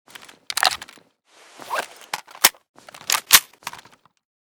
pp2000_reload_empty.ogg.bak